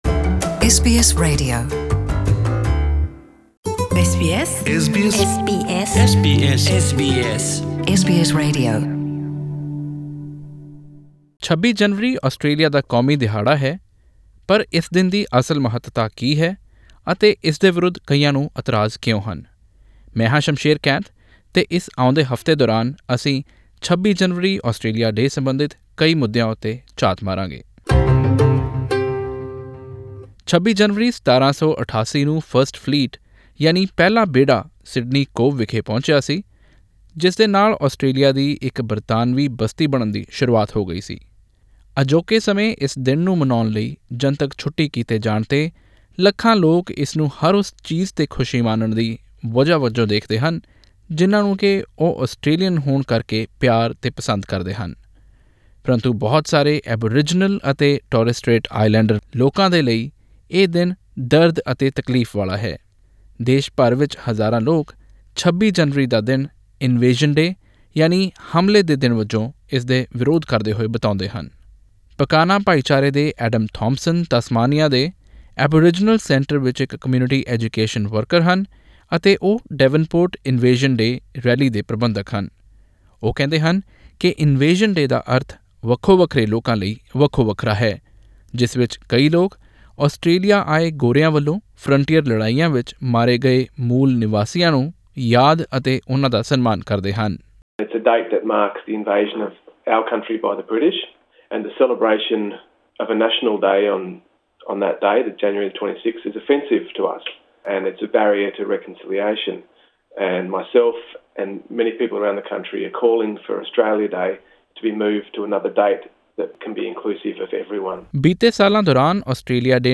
We explain in this audio feature why Australia Day is controversial.